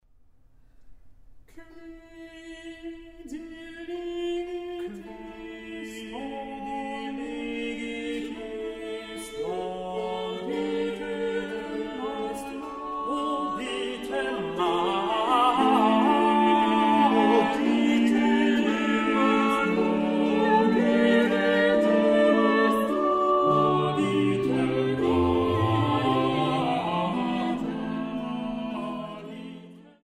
Leitung und Orgel
SEX VOCUM